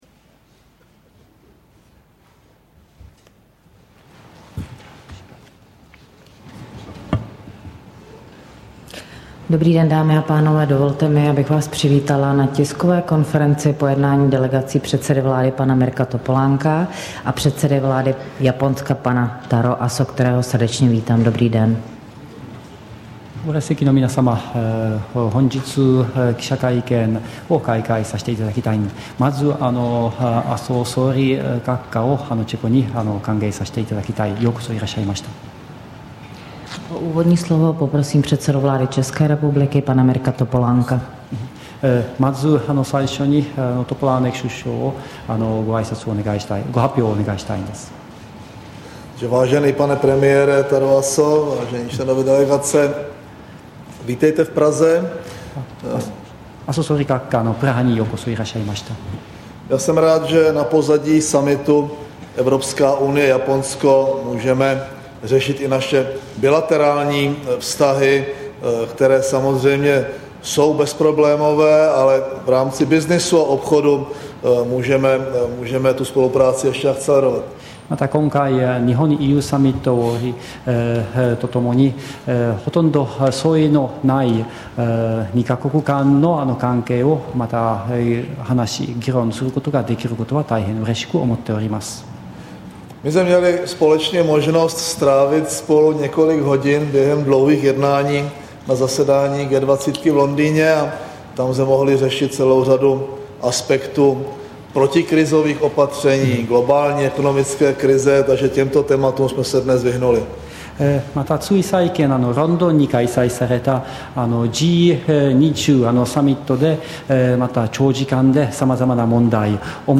Tisková konference po setkání českého a japonského premiéra, 3. května 2009